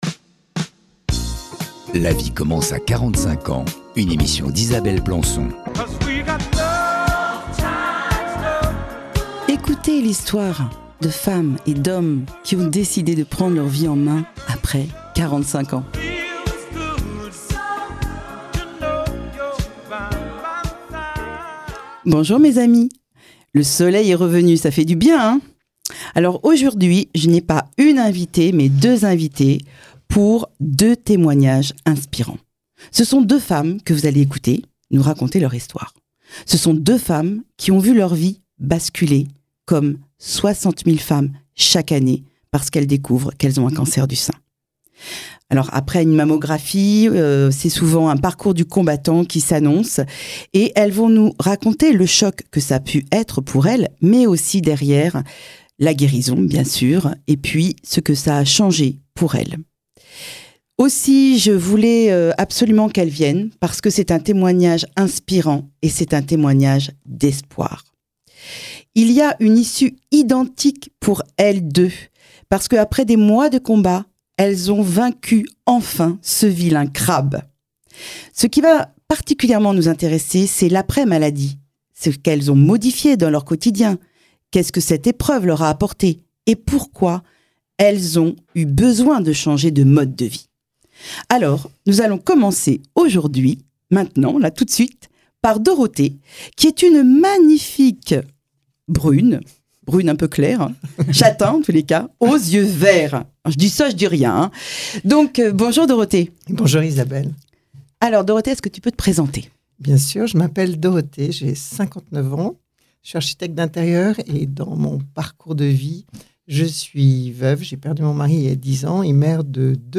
2 femmes